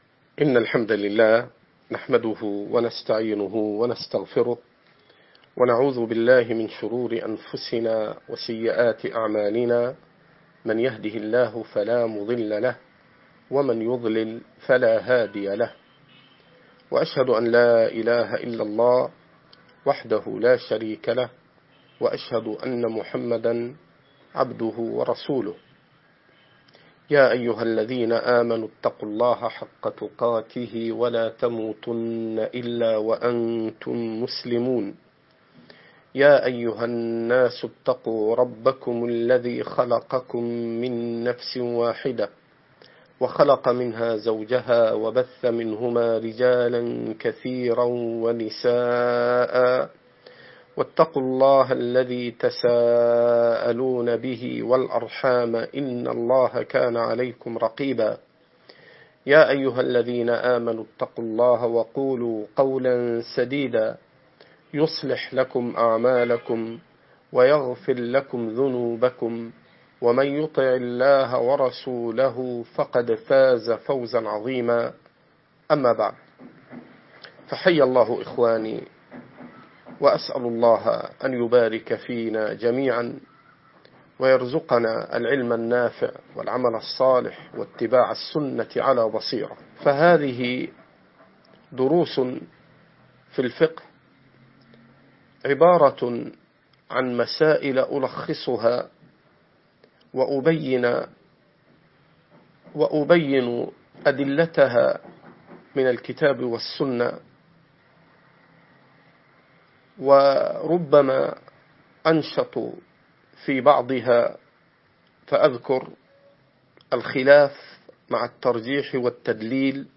مسائل في الفقه - الدرس الأول